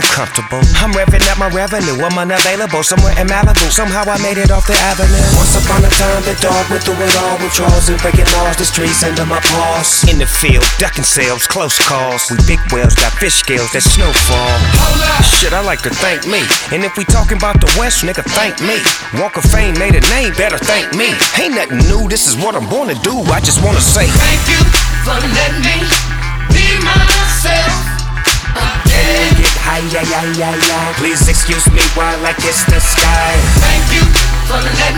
пробивного бита и лаконичного сэмпла
Жанр: Хип-Хоп / Рэп